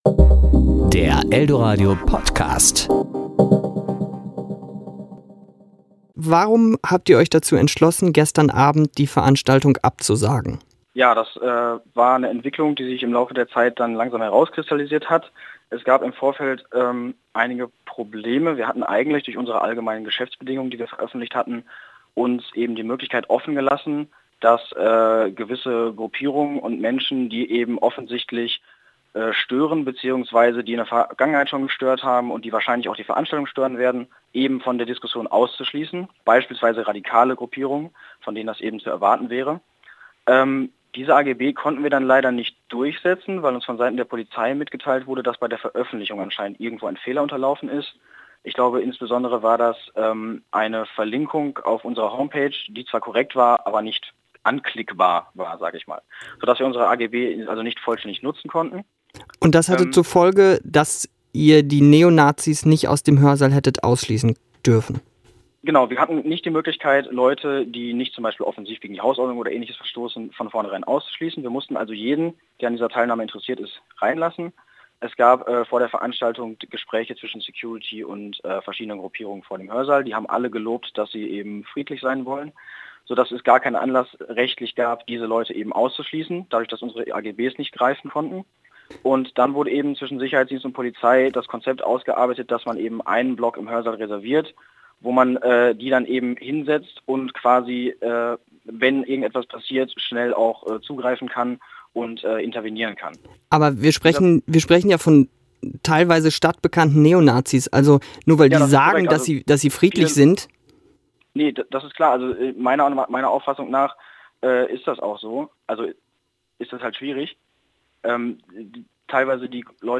podcast_interview_schwulenreferat.mp3